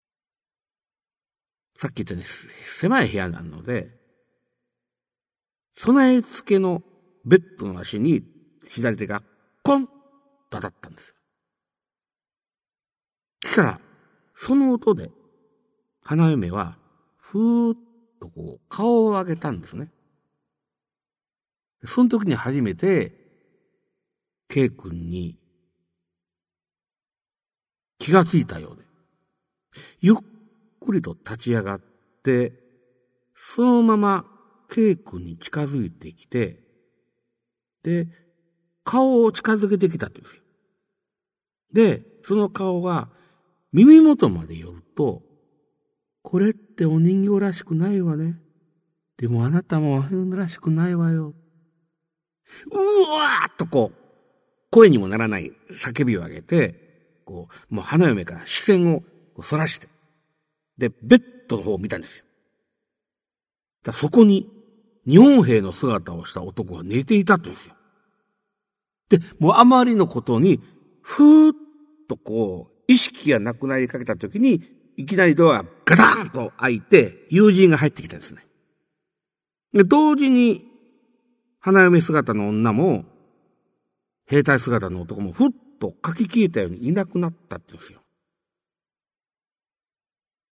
[オーディオブック] 市朗怪全集 五十五
実話系怪談のパイオニア、『新耳袋』シリーズの著者の一人が、語りで送る怪談全集! 1990年代に巻き起こったJホラー・ブームを牽引した実話怪談界の大御所が、満を持して登場する!!